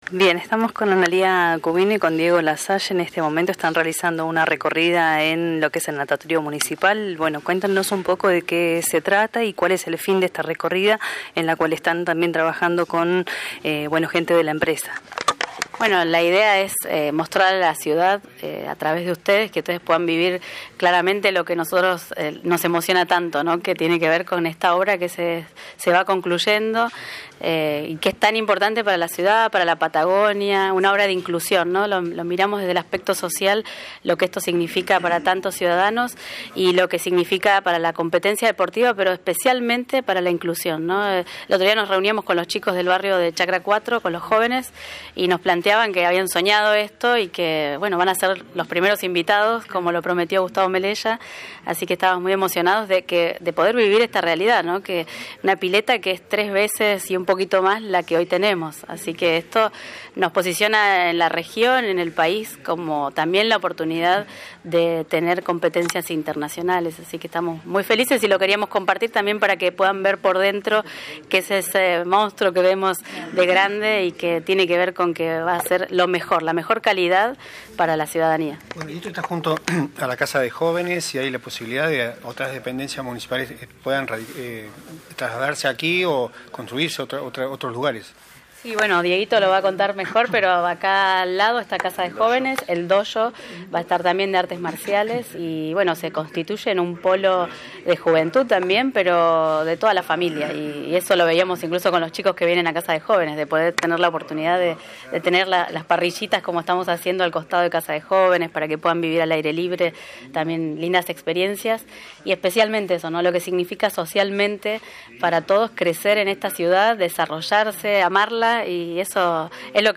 Al respecto dialogaron con este medio Analia Cubino, Secretaria de Desarrollo Social y el Director de la agencia municipal de Deportes y Juventud, Diego Lasalle.